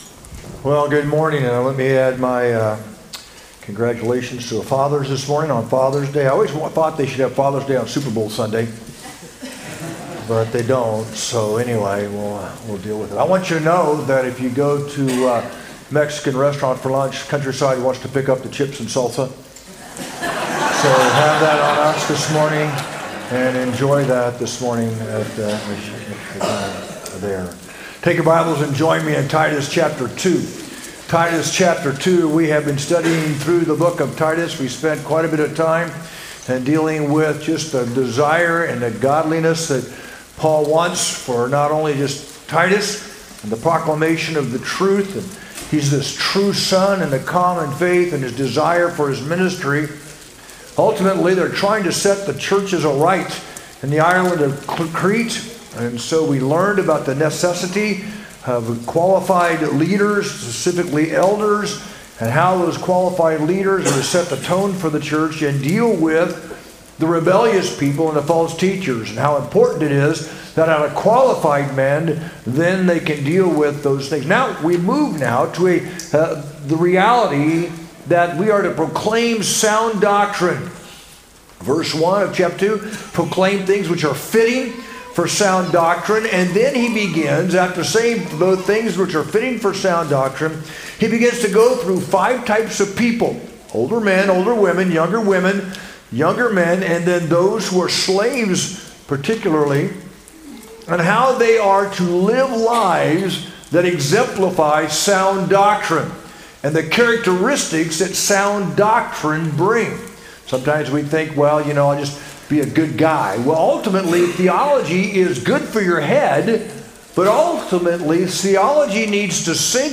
sermon-6-15-25.mp3